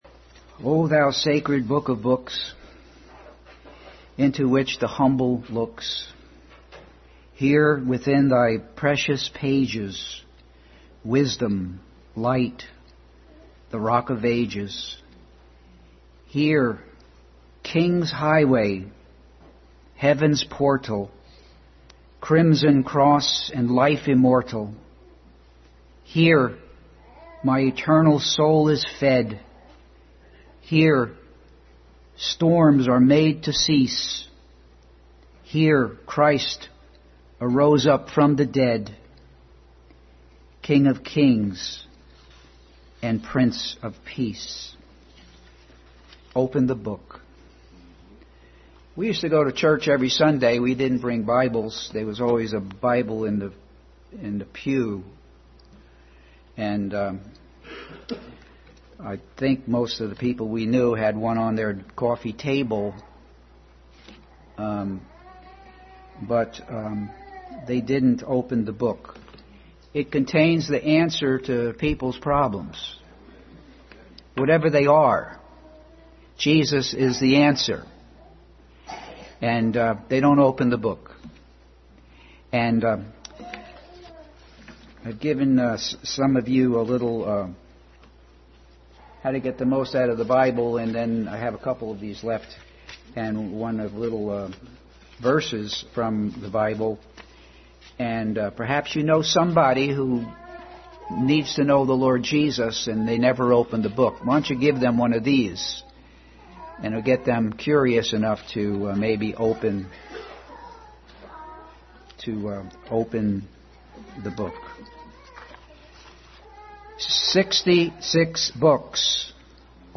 Passage: Luke 4:14-19, Psalm 22:11, Matthew 15:21-28, John 14:1-6, Matthew 26:36-46, Luke 16:19-31 Service Type: Family Bible Hour